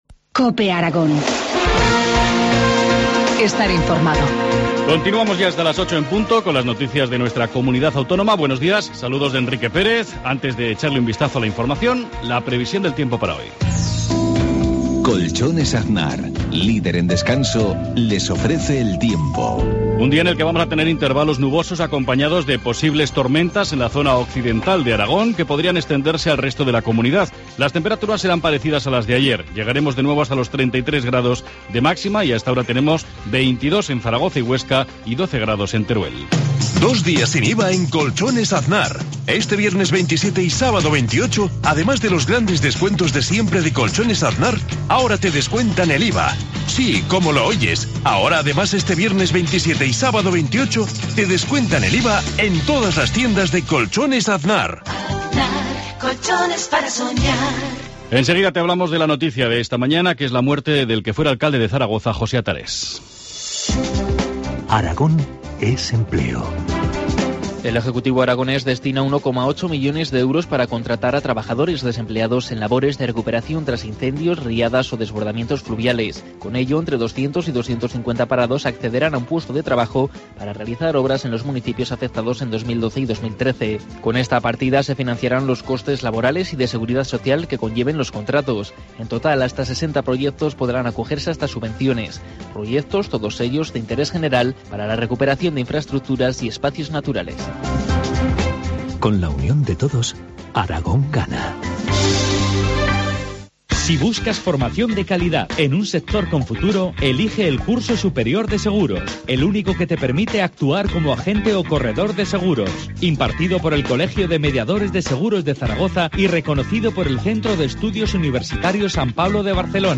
Informativo matinal, viernes 27 de septiembre, 7.53 horas